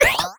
pgs/Assets/Audio/Comedy_Cartoon/cartoon_boing_jump_04.wav at master
cartoon_boing_jump_04.wav